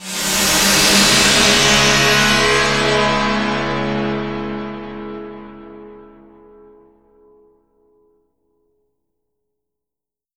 Index of /90_sSampleCDs/E-MU Producer Series Vol. 4 – Denny Jaeger Private/Effects/Piano FX